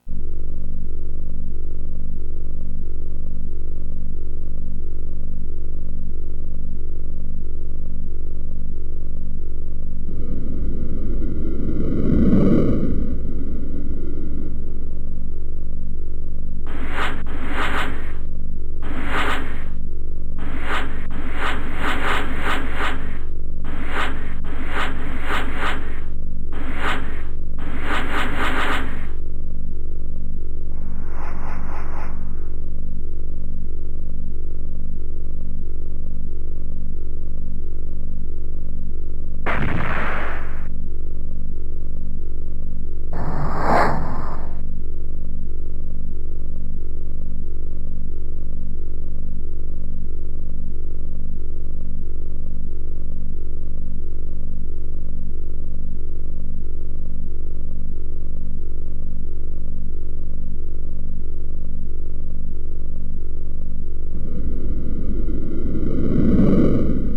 Are there any sounds that just utterly creep you out? For me, the 3DO boot up sequence does.